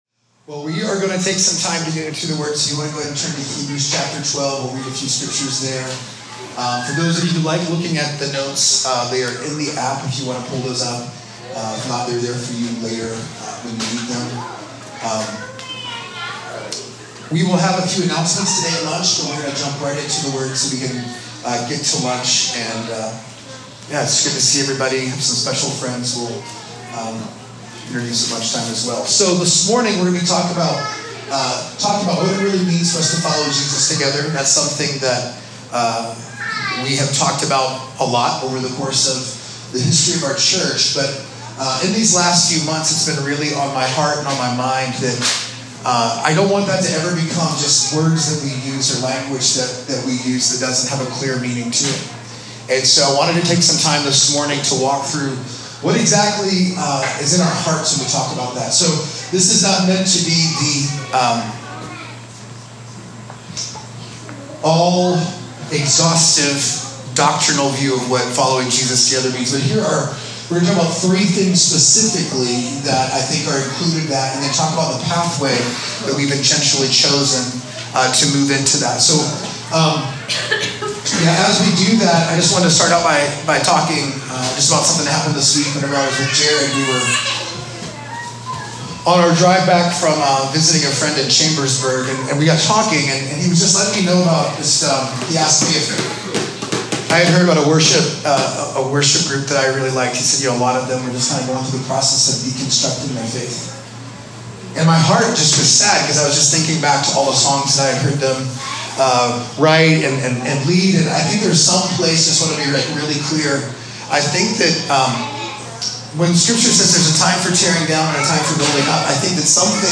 Current Teaching